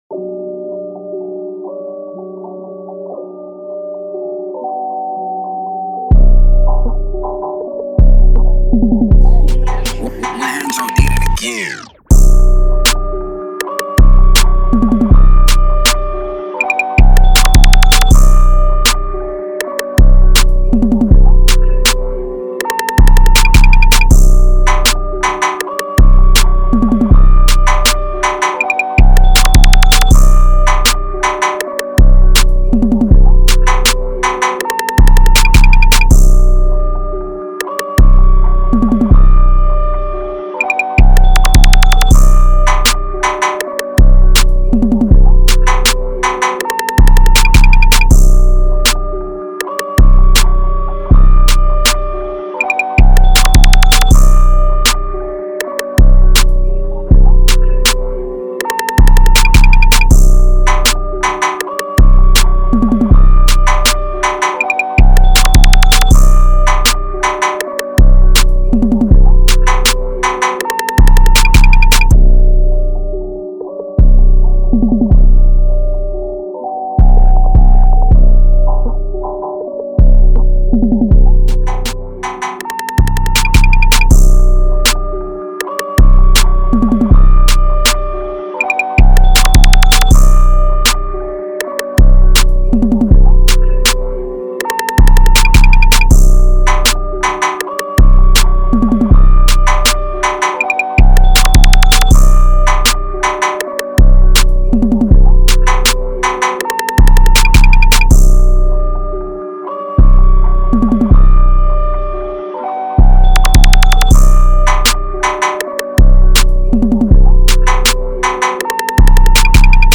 official instrumental
Trap Instrumentals